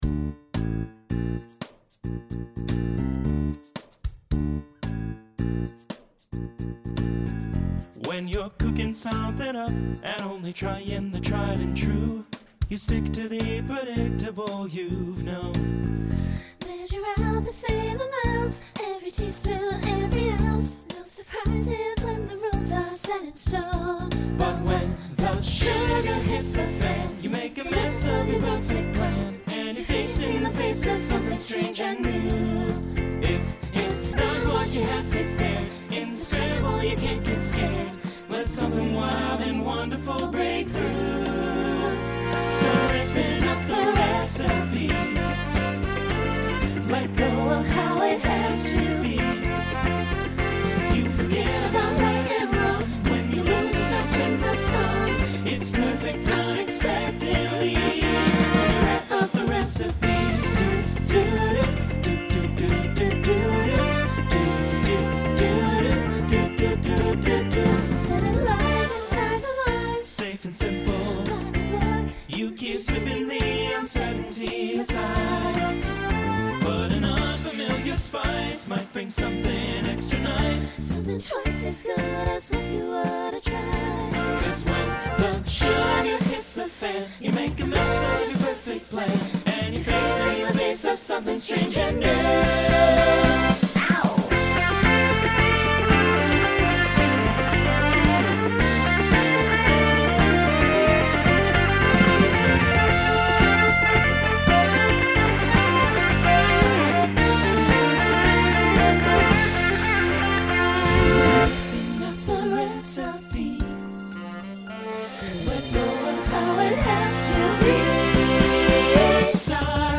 New SSA voicing for 2025.
Genre Broadway/Film , Pop/Dance Instrumental combo